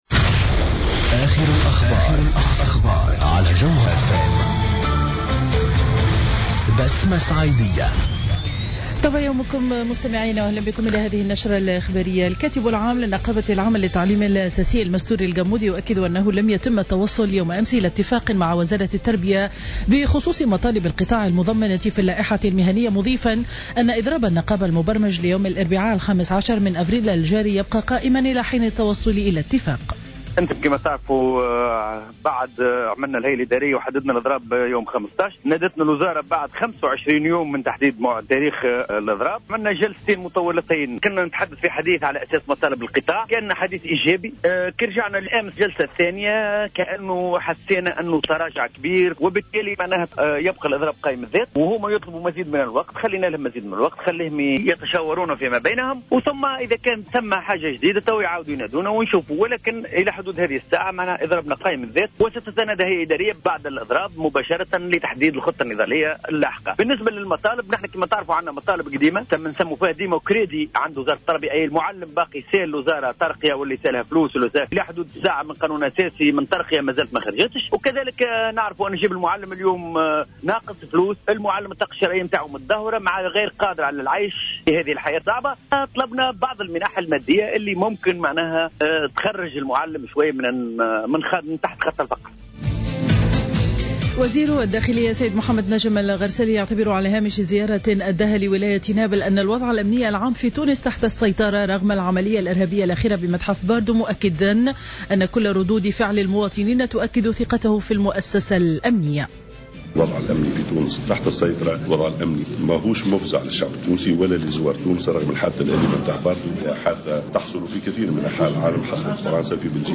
نشرة أخبار السابعة صباحا ليوم الأحد 12 أفريل 2015